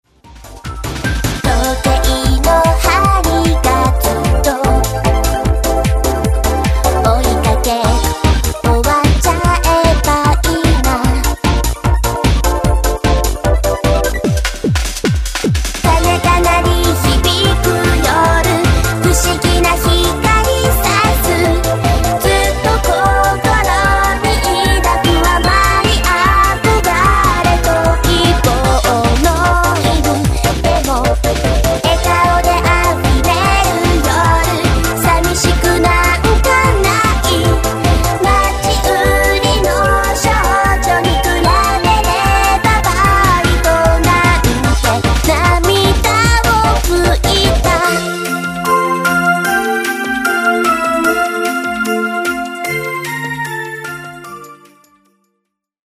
全曲クロスフェードデモ
Vocal/Chorus/Lyrics/Compose/etc...
Solo Guitar*track03